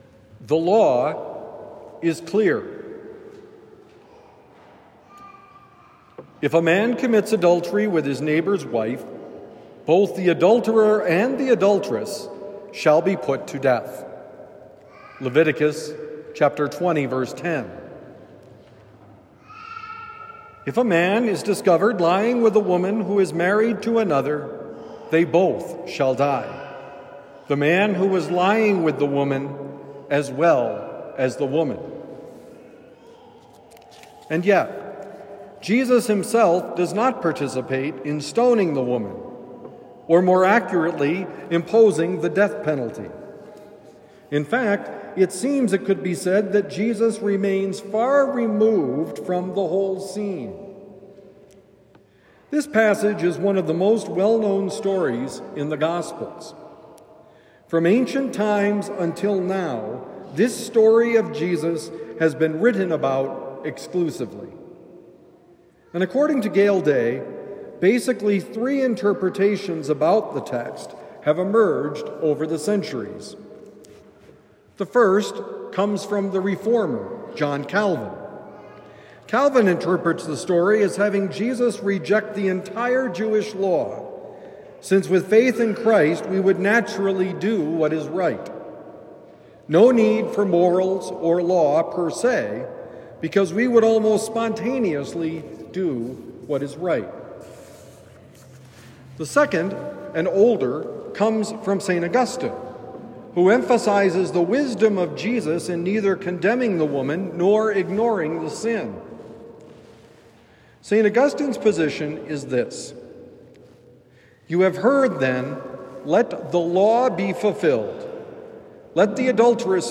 Sin no more: Homily for Sunday, April 6, 2025